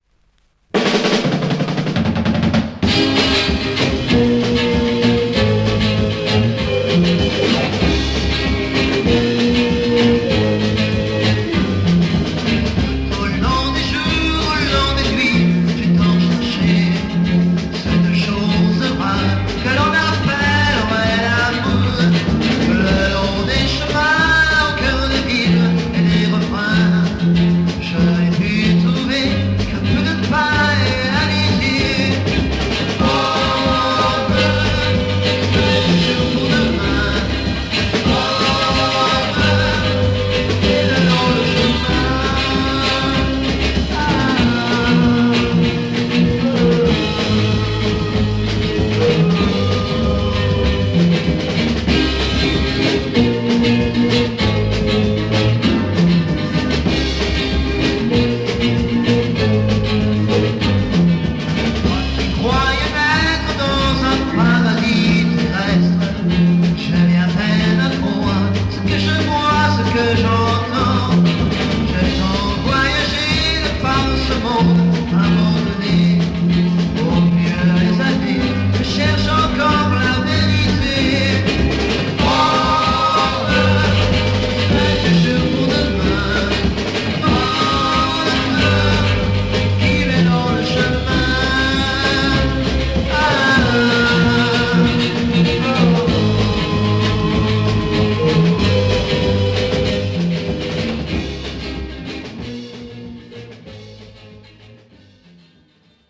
Private Garage beat French EP